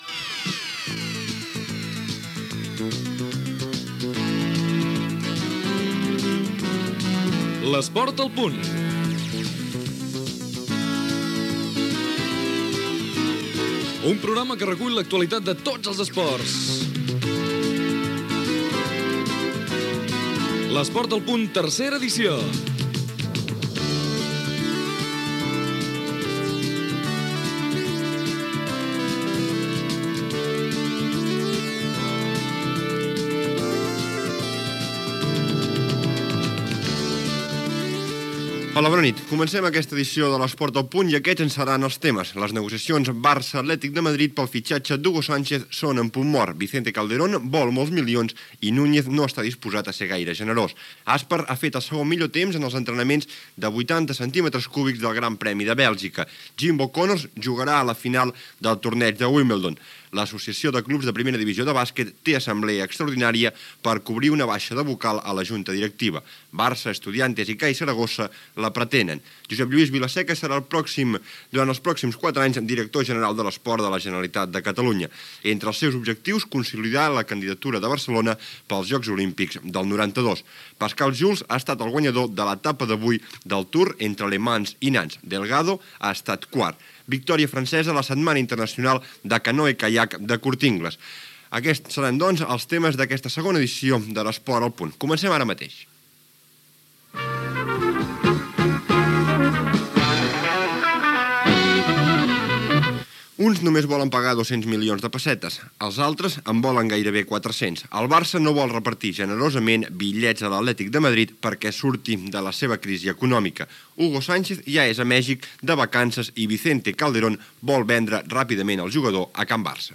Careta del programa (en veu de Miquel Calçada)
Gènere radiofònic Esportiu